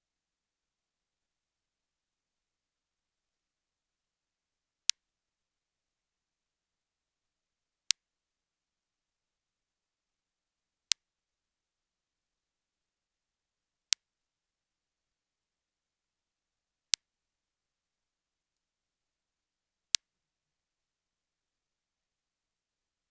Circular TMS Coil
Transcranial Magnetic Stimulator
Click here to download a sample of the sound of the coil when it is triggered. (The sound was measured at a distance of 5cm away from the coil, at 100% power.)